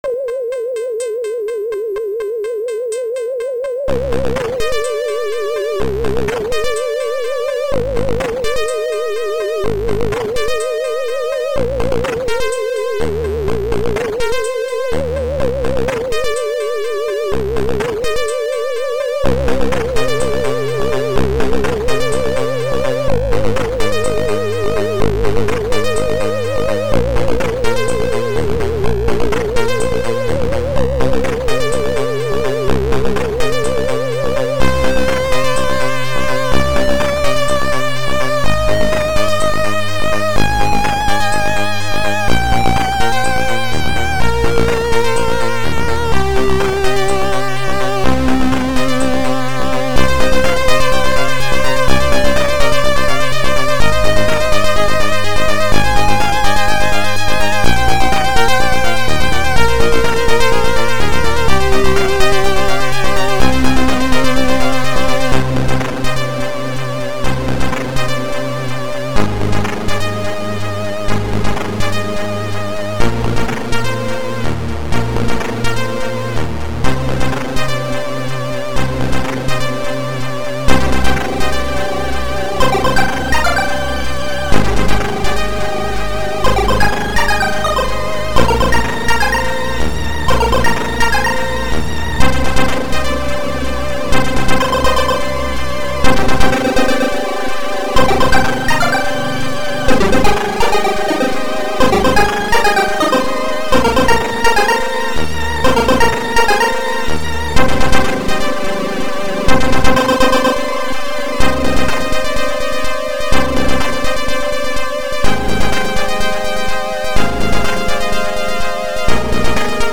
Synth